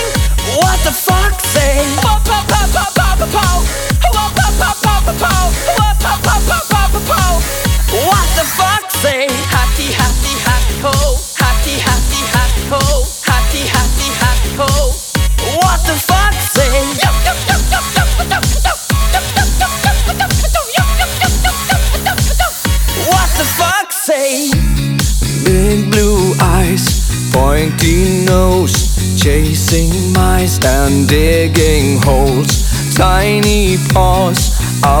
Жанр: Поп / Электроника / Рок